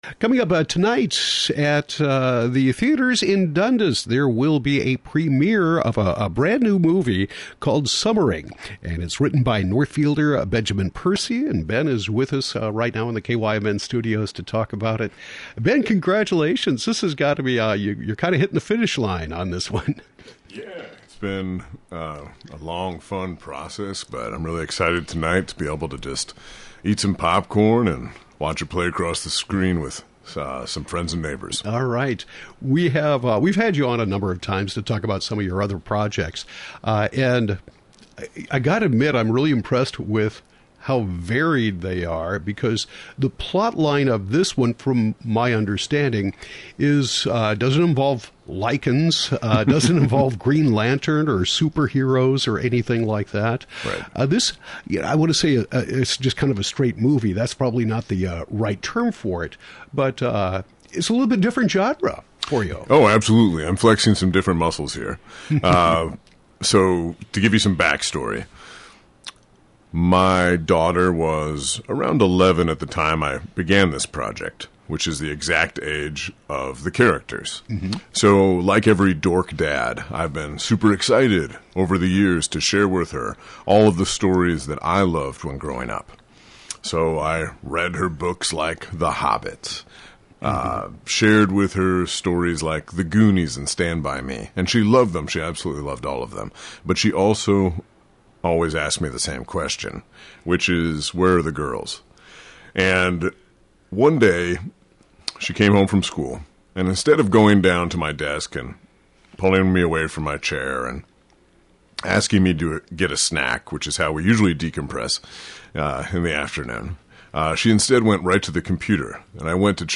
Local author and screenwriter Ben Percy talks about his movie “Summering” which premieres tonight, August 11, 7 pm at Cannon Valley Cinema.